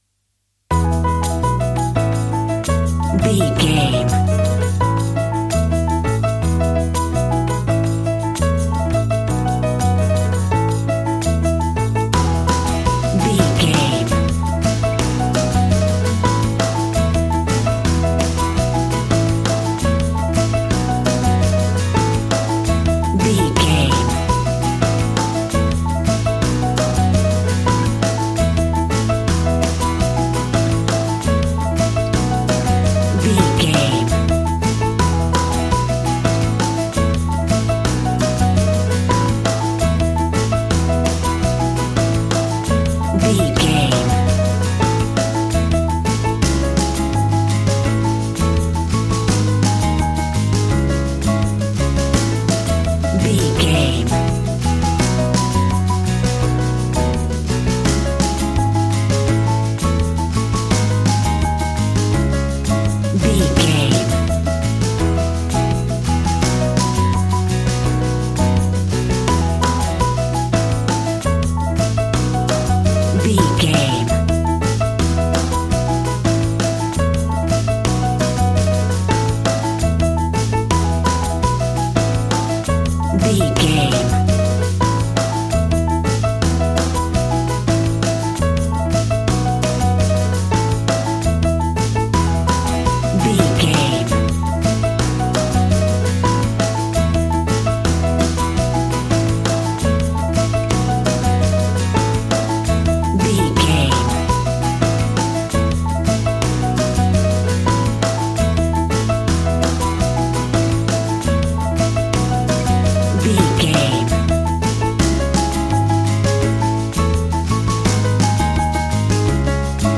Ionian/Major
funky
energetic
romantic
percussion
electric guitar
acoustic guitar